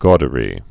(gôdə-rē)